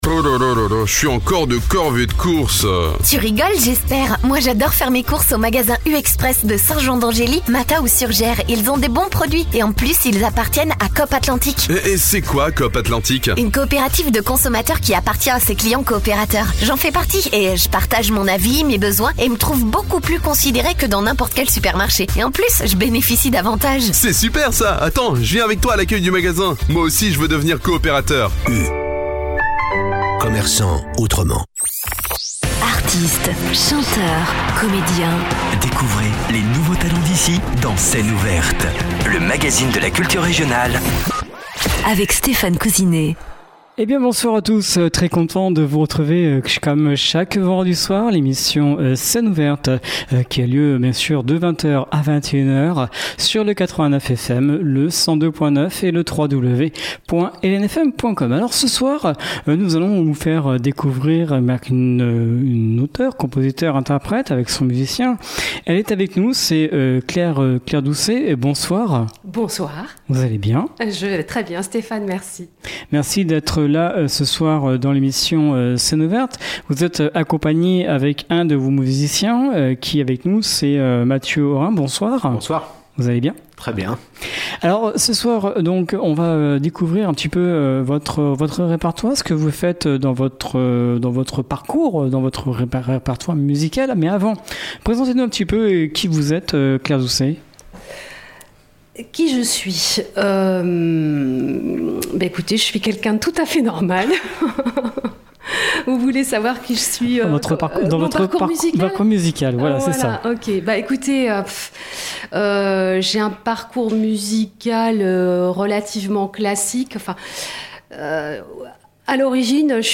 Scène ouverte avec la chanteuse